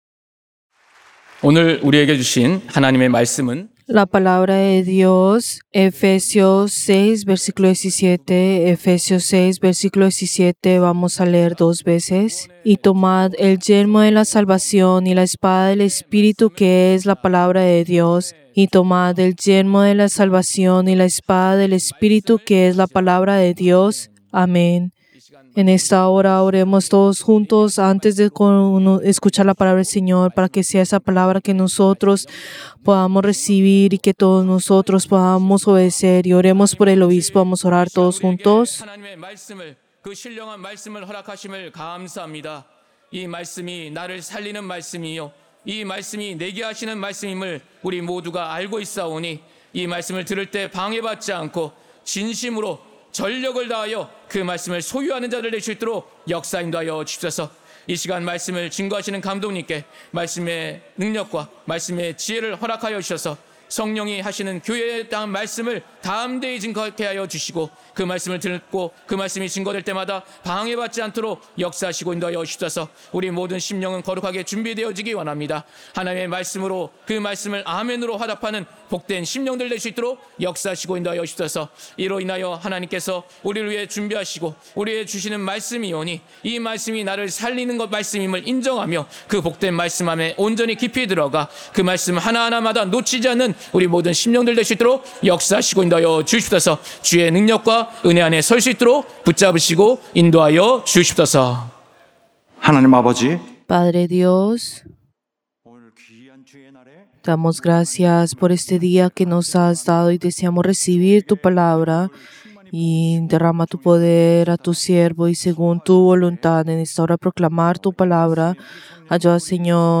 Servicio del Día del Señor del 3 de agosto del 2025